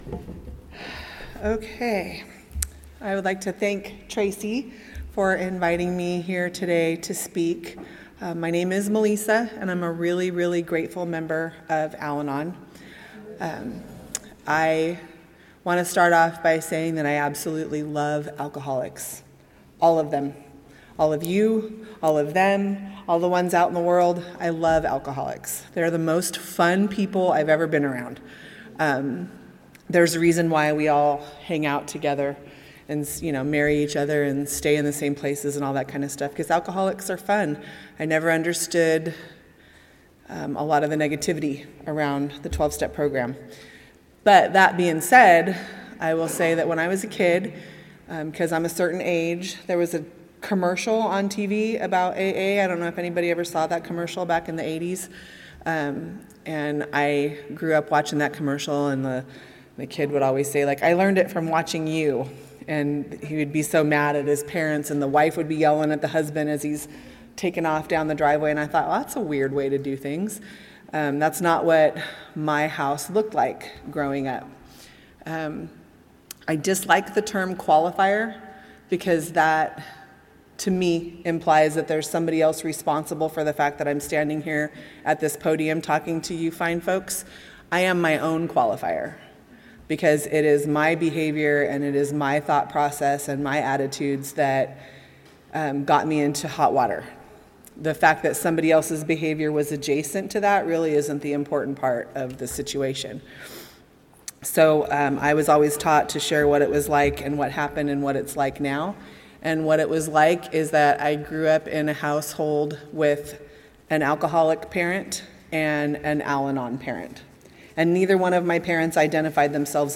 34th Annual Indian Wells Valley Roundup - Al-Anon Luncheon Speaker